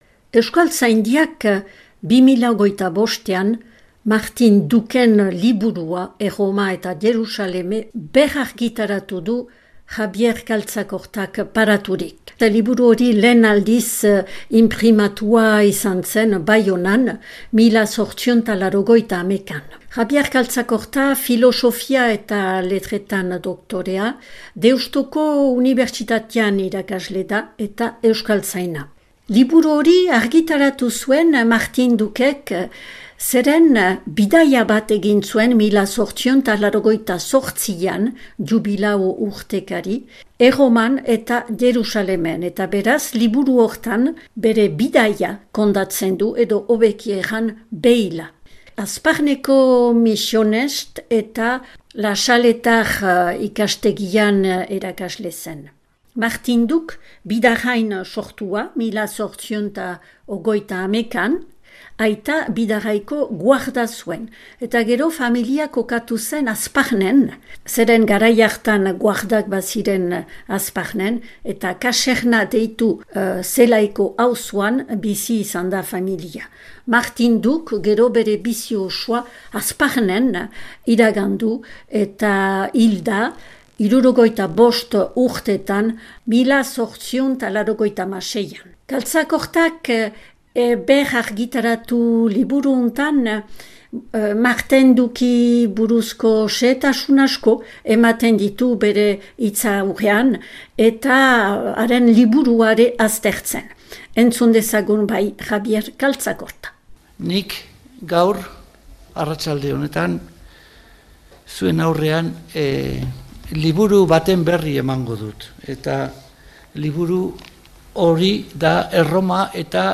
Baionako Euskal Museoan aurkeztua 2025eko azaroaren 20an.